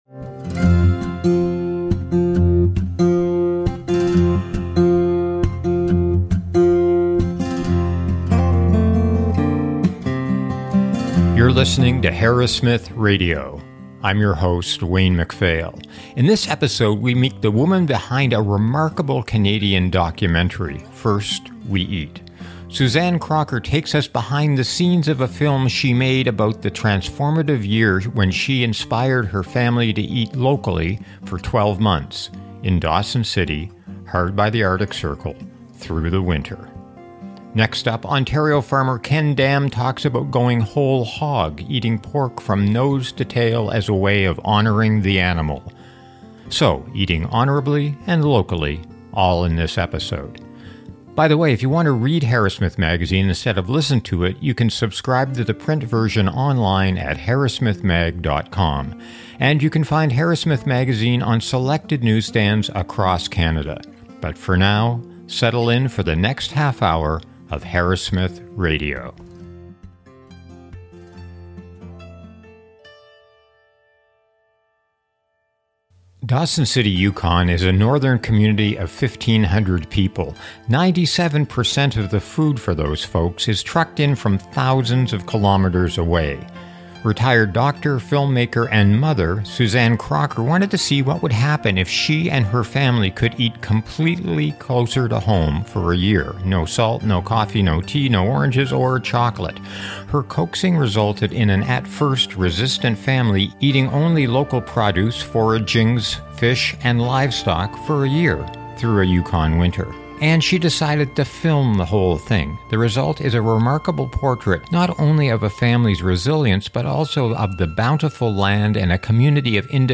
A documentary about eating locally for a year in the North. And eating pork, whole hog.